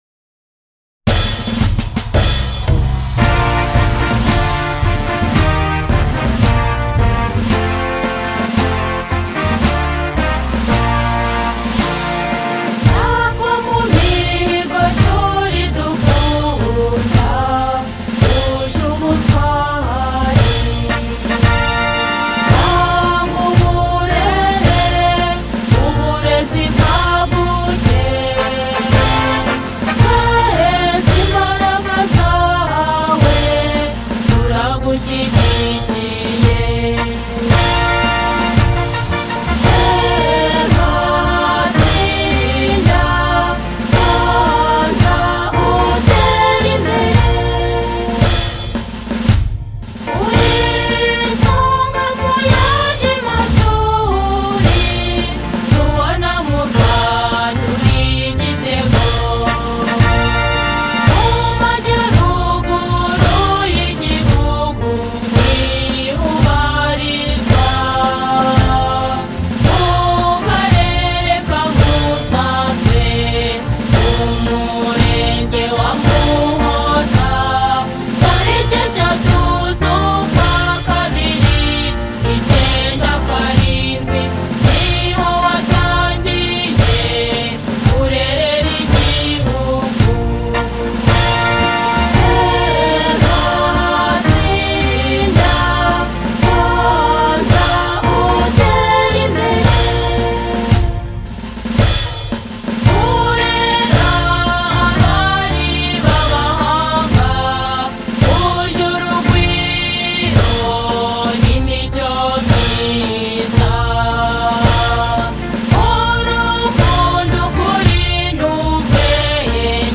Play the school hymn